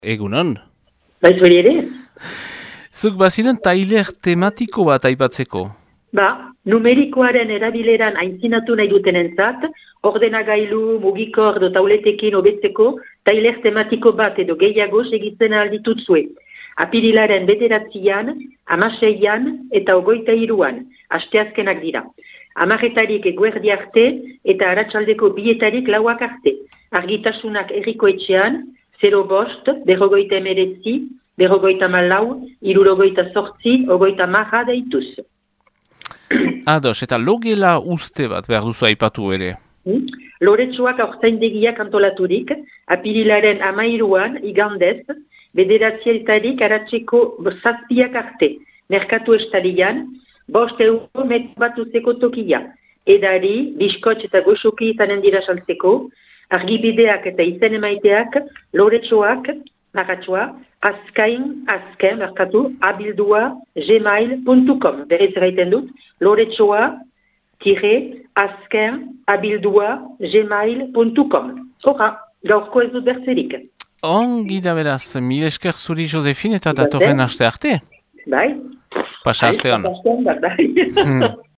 BERRIKETARIAK | AZKAINE